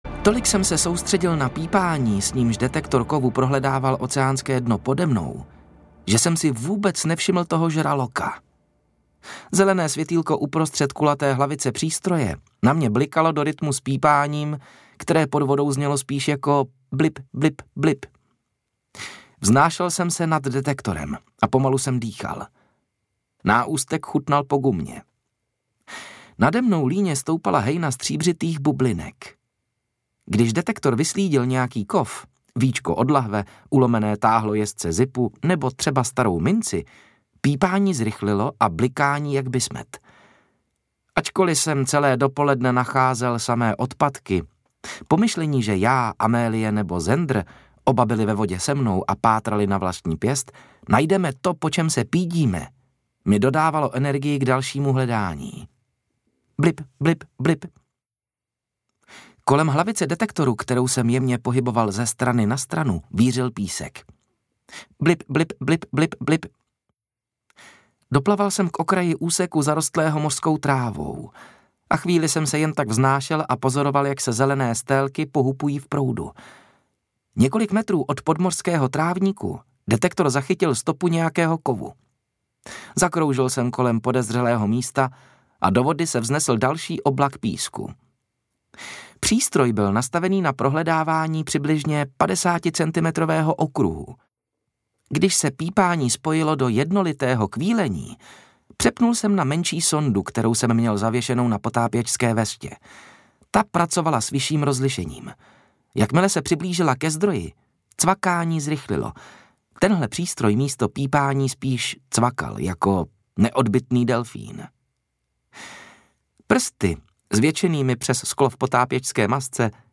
Délka: 16 h 53 min Interpret: Pavel Soukup Vydavatel: Tympanum Vydáno: 2023 Série: Vinnetou Jazyk: český Typ souboru: MP3 Velikost: 952 MB
Audioknihy